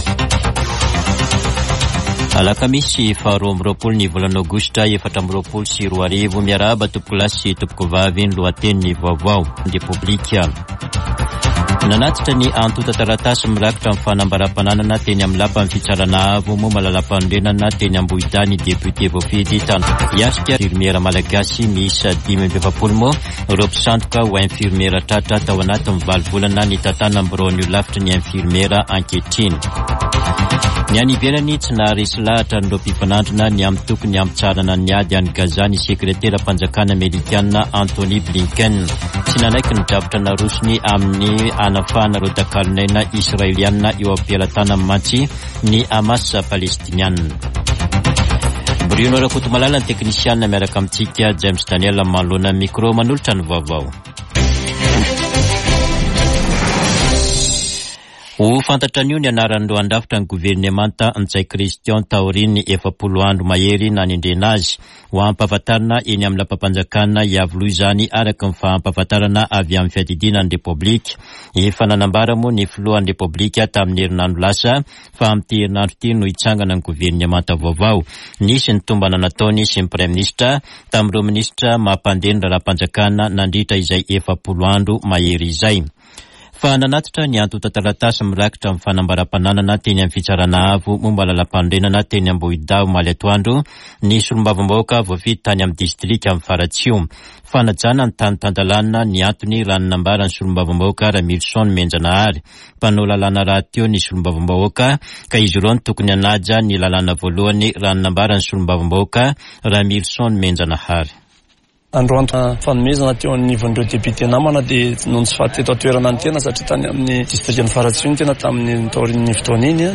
[Vaovao maraina] Alakamisy 22 aogositra 2024